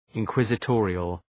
Προφορά
{ın,kwızı’tɔ:rıəl} (Επίθετο) ● ανακριτικός